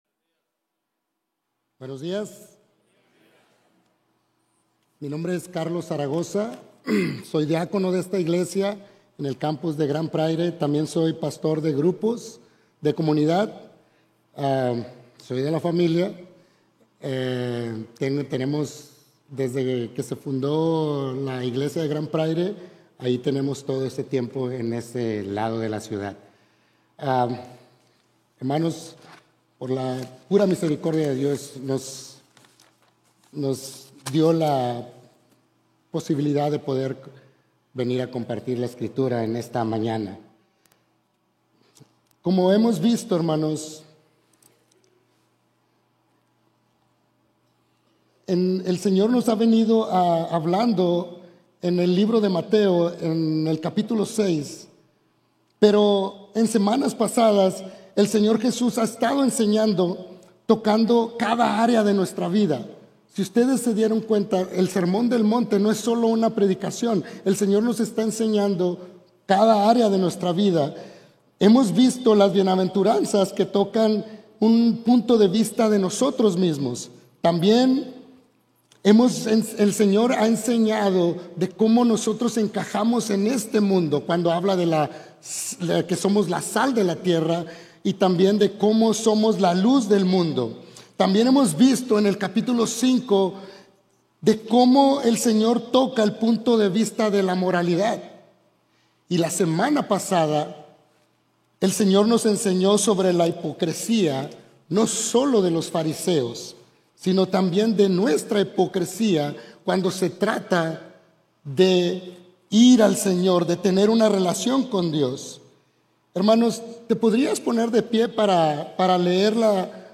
MARCH-16-SPANISH-SERMON-AUDIO-v1.mp3